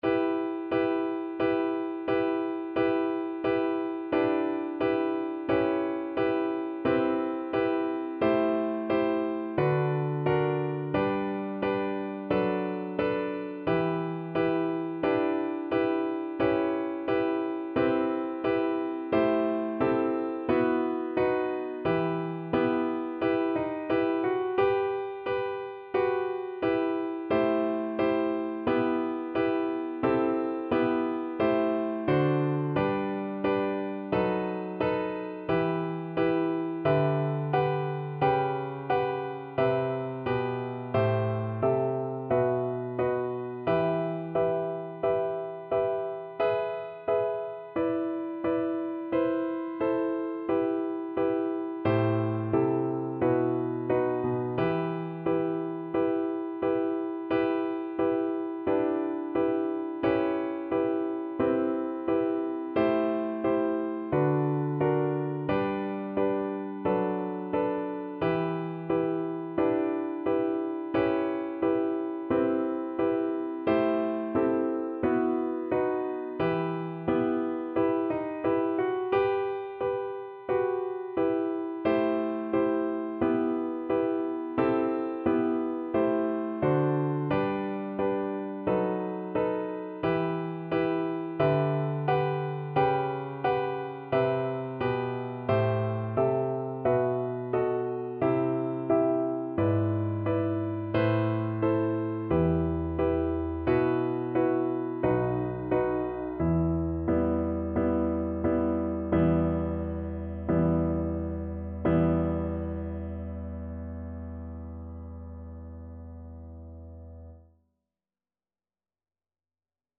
E minor (Sounding Pitch) (View more E minor Music for Viola )
4/4 (View more 4/4 Music)
Andante =c.88
Classical (View more Classical Viola Music)